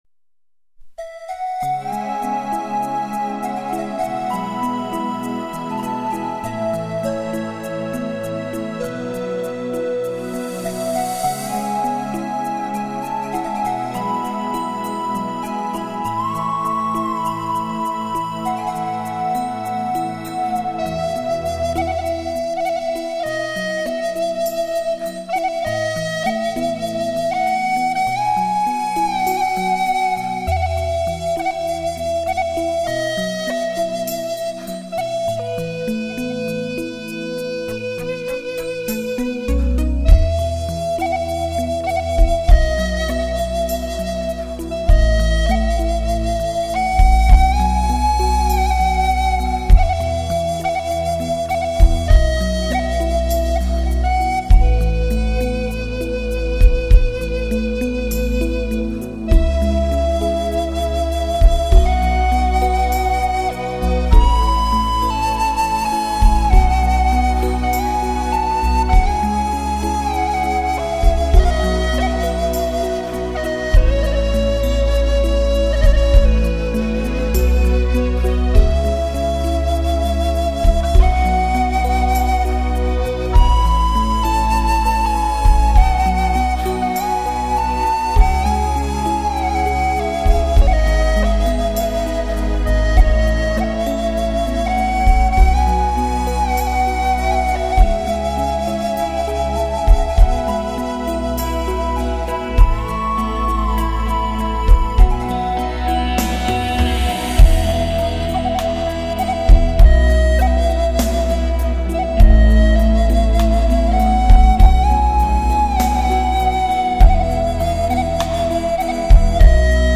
音乐类型:民乐
优雅竖笛伴奏，闭上眼睛，放松身心，任弥漫的乐声领你走回混沌初开的世间……★